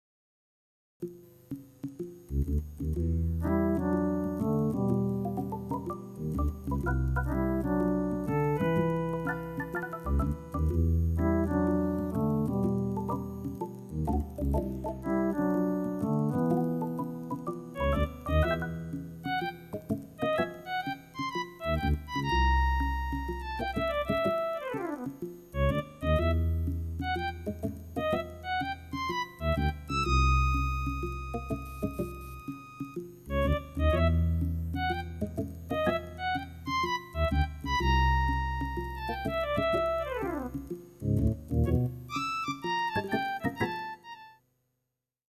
Stereo & multi-timbral recording